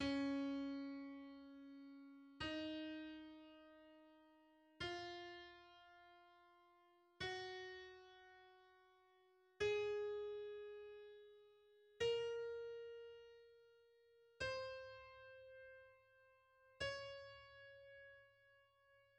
Gama Des-dur zawiera dźwięki: des, es, f, ges, as, b, c. Tonacja Des-dur zawiera pięć bemoli.
Gama Des-dur zapisana za pomocą